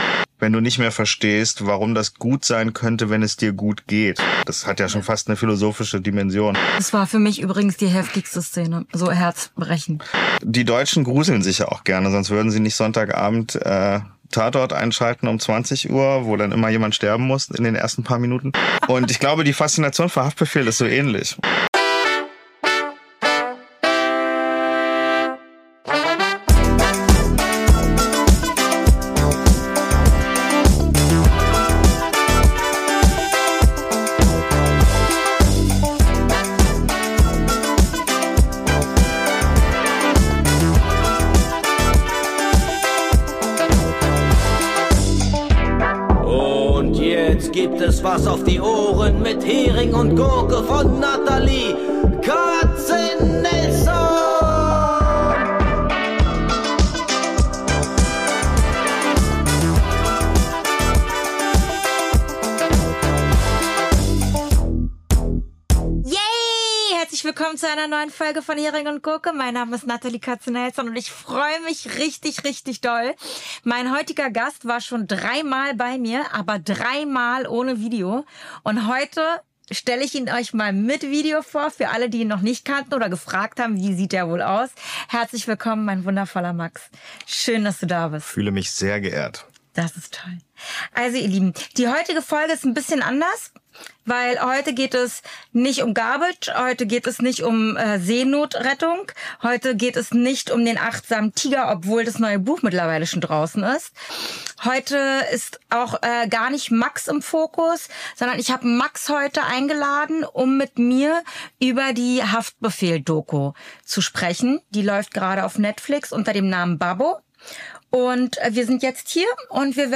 Mein Gast.
Zwei Mikrofone.
Ein Gespräch, das bleibt.